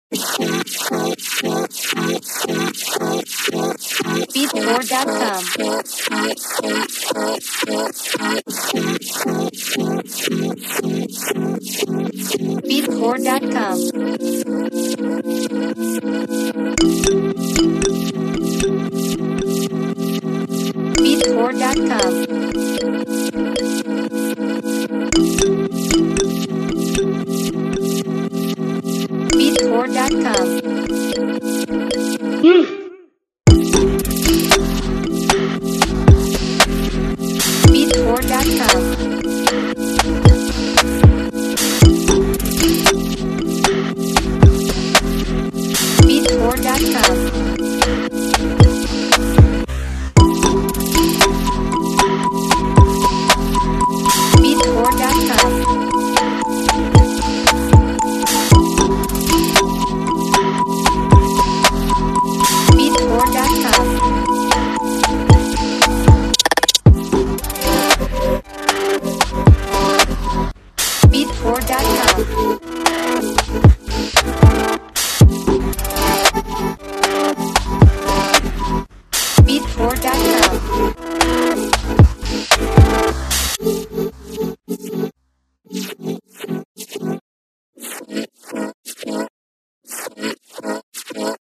Genre: Future Bass Mood: Vintage Old
Time Signature: 4/4
Instruments: Synthesizer Xylophone Vocal